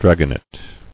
[drag·on·et]